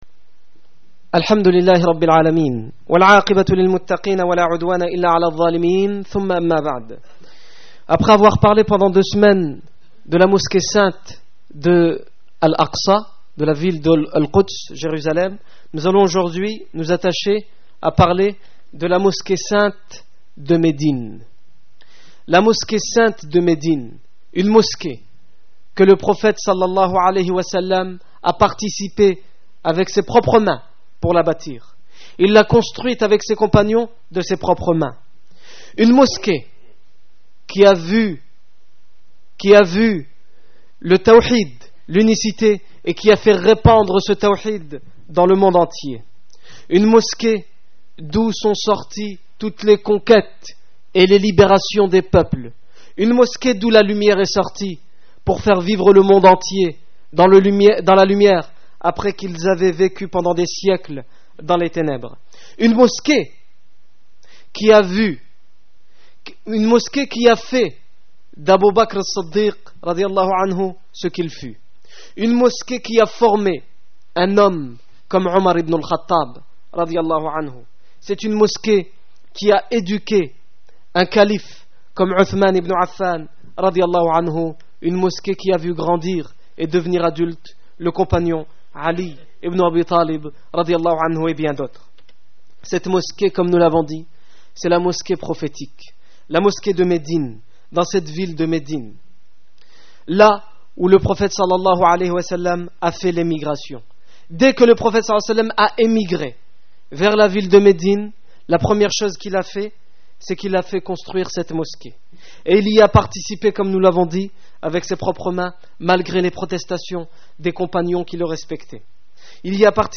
Discours du 23 octobre 2009
Accueil Discours du vendredi Discours du 23 octobre 2009 L'histoire de la Mosquée du Prophète à Médine